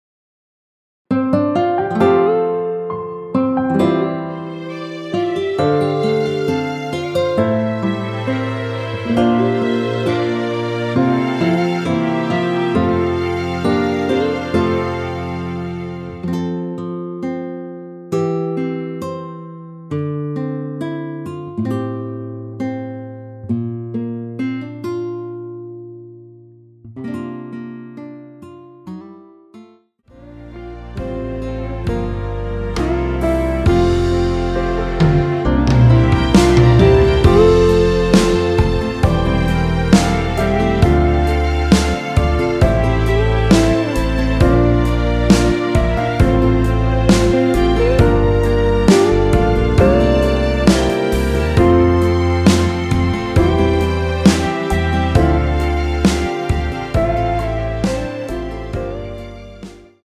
원키 MR입니다.
앞부분30초, 뒷부분30초씩 편집해서 올려 드리고 있습니다.
중간에 음이 끈어지고 다시 나오는 이유는